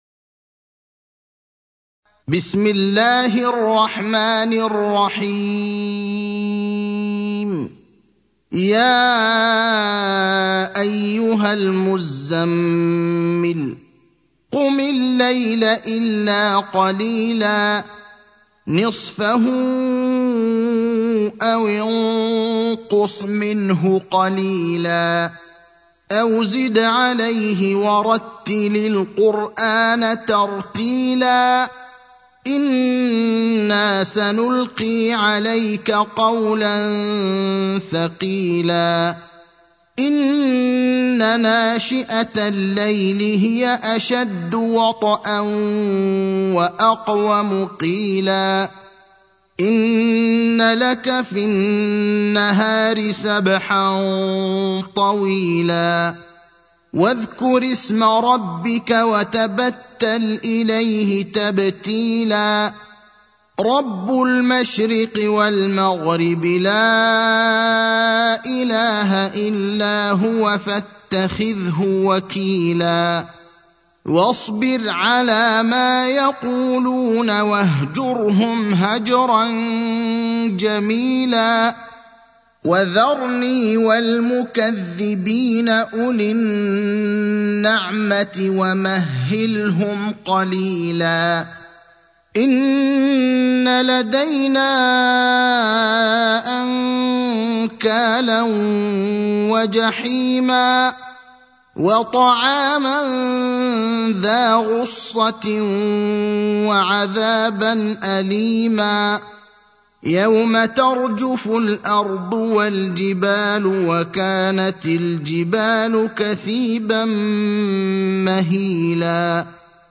المكان: المسجد النبوي الشيخ: فضيلة الشيخ إبراهيم الأخضر فضيلة الشيخ إبراهيم الأخضر المزمل (73)